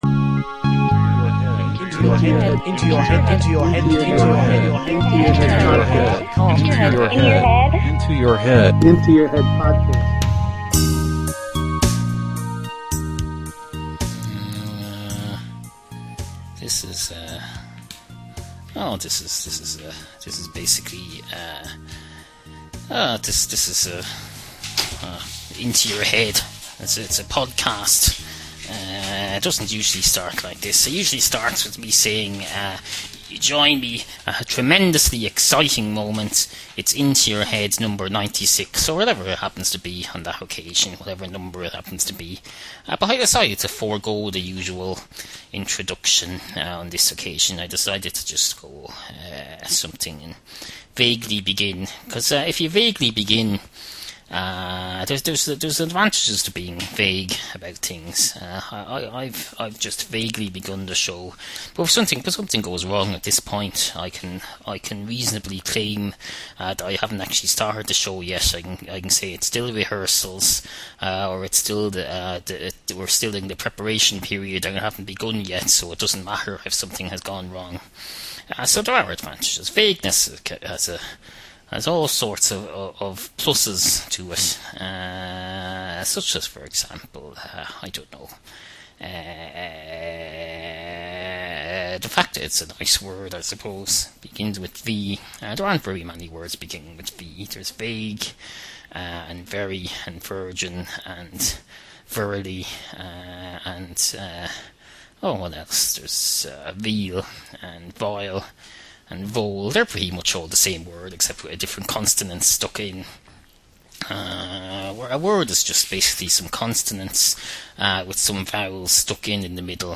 Also: We experiment with our first ever entirely instrumental cat song.